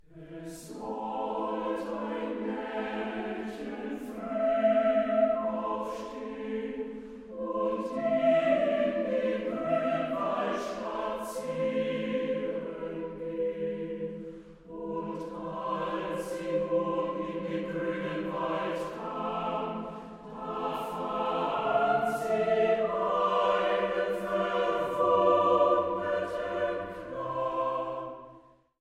chormusikalisches A-cappella-Werk
für gemischten Chor und Frauenstimmen